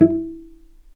healing-soundscapes/Sound Banks/HSS_OP_Pack/Strings/cello/pizz/vc_pz-E4-mf.AIF at bf8b0d83acd083cad68aa8590bc4568aa0baec05
vc_pz-E4-mf.AIF